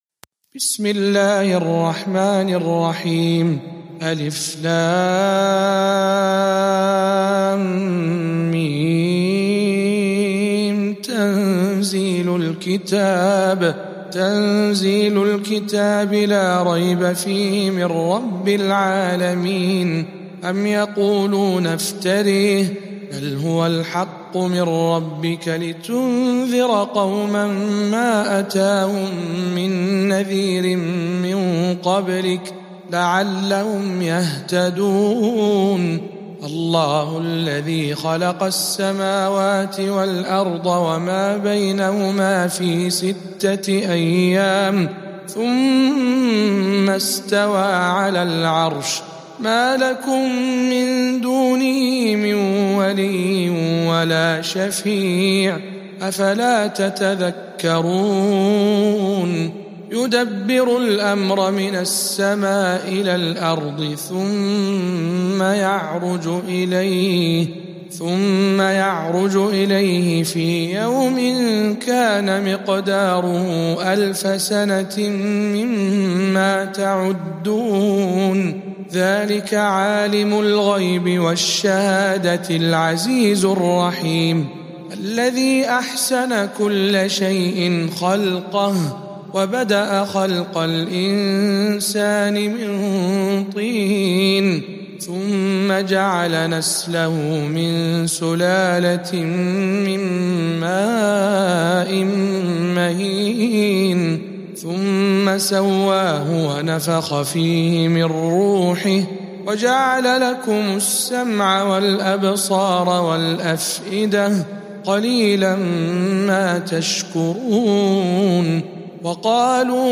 سورة السجدة برواية الدوري عن أبي عمرو